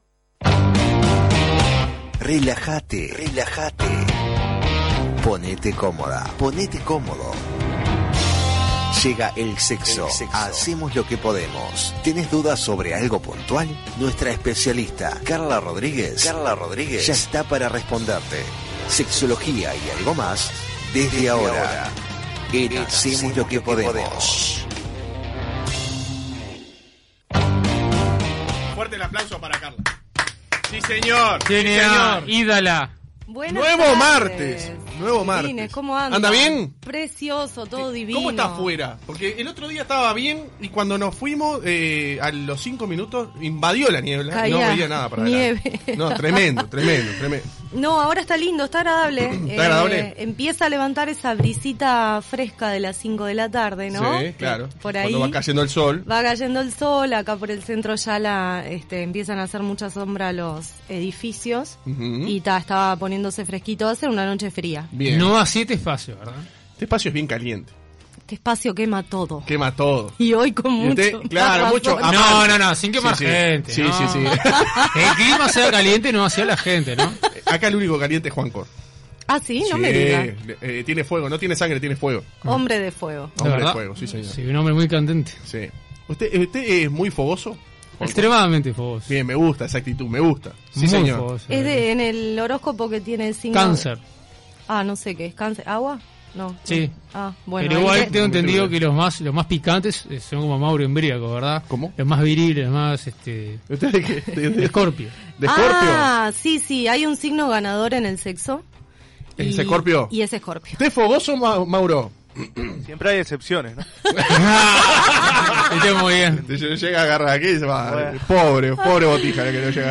Desde crisis pasajeras hasta adrenalina de lo prohibido son algunos de los fundamentos pero además, los oyentes contaron experiencias propias que algunas te sorprenderán.